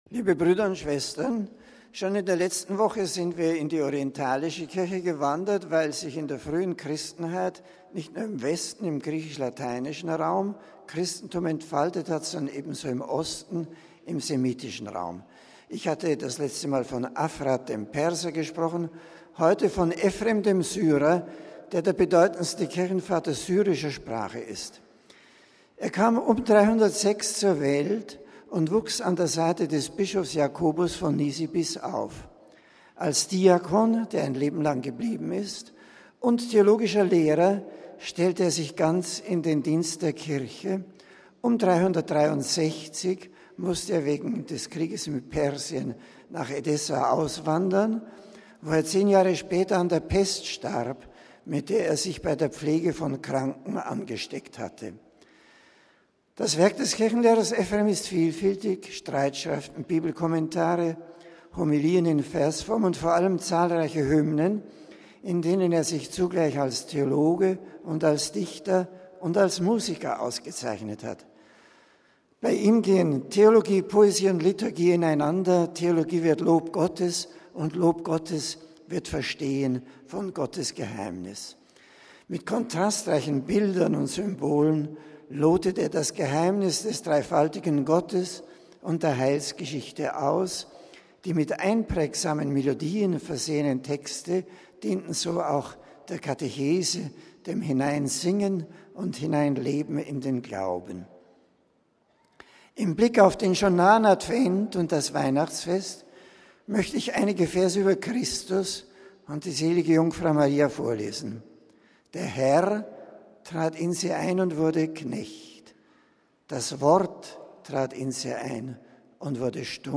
Generalaudienz: Die Wurzeln des Christentums
Zur heutigen Aktualität sagte Benedikt XVI.: